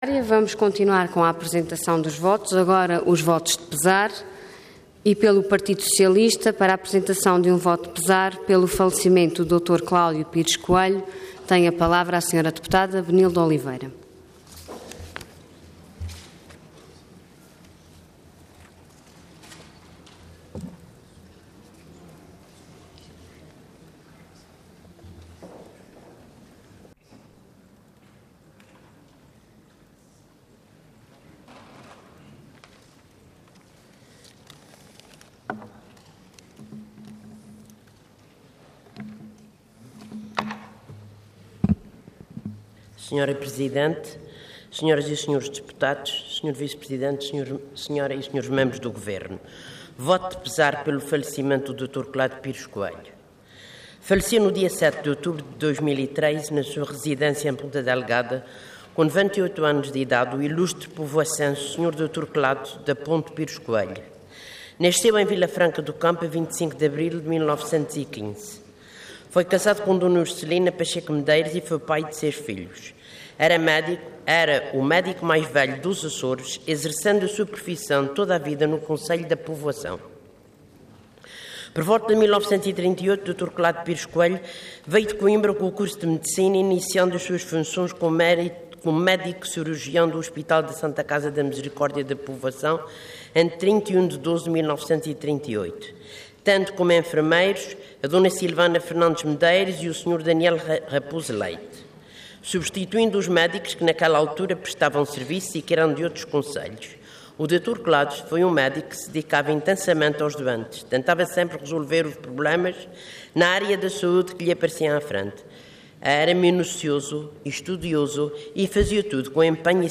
Intervenção Voto de Pesar Orador Benilde Oliveira Cargo Deputada Entidade PS